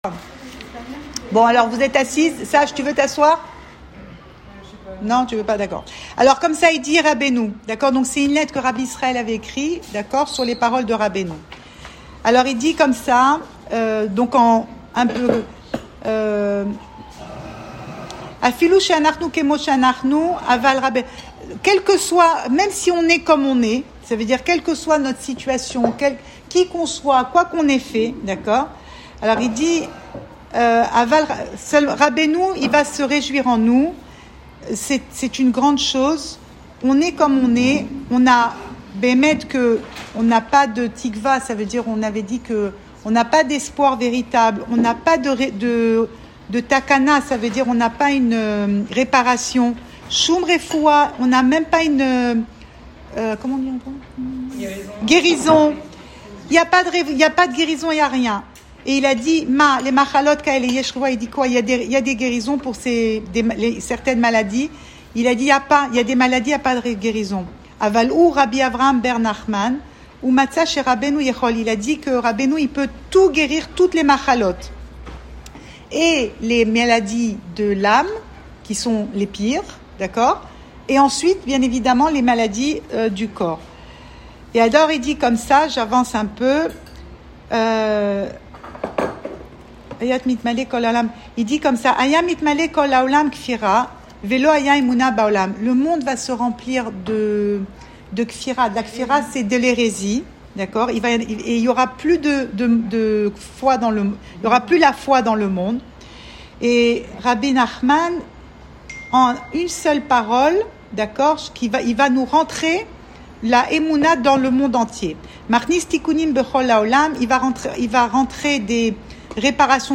Cours audio
Enregistré à Tel Aviv